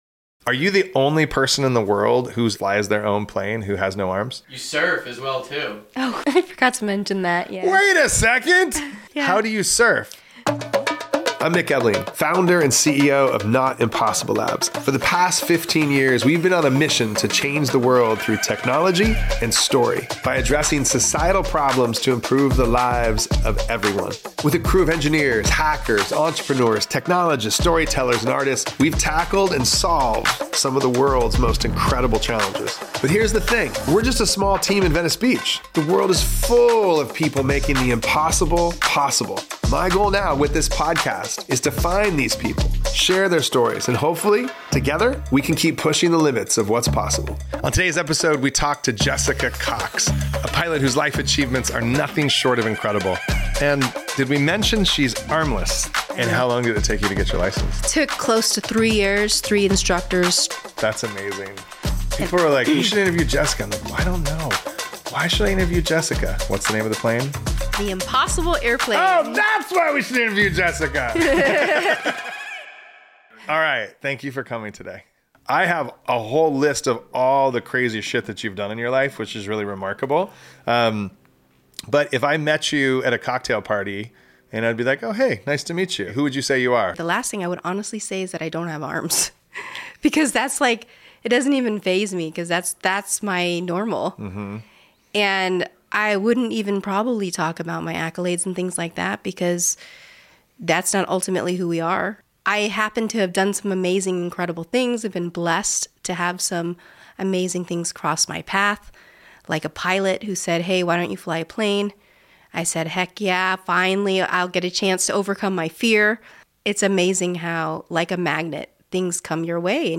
This conversation dives deep into resilience, adaptability, and the mindset that turns obstacles into opportunity.